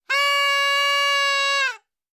Звуки праздничной дудки
Звук этой дудки чаще всего слышен на Дне рождения